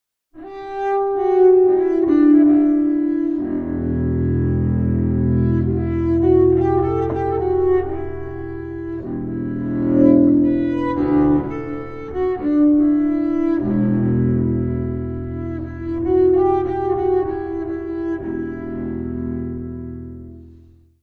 : stereo; 12 cm
Music Category/Genre:  Jazz / Blues